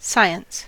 science: Wikimedia Commons US English Pronunciations
En-us-science.WAV